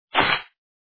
bag_place.wav